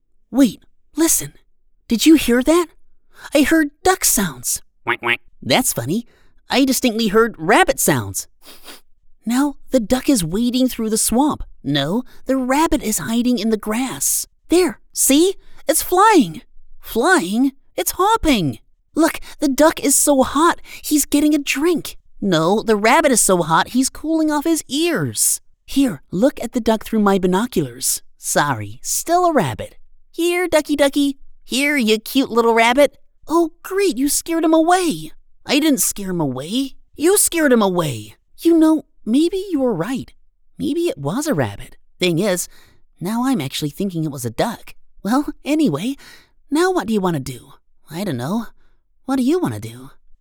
Child Voice Demos
Natural Fun Kid Children Youth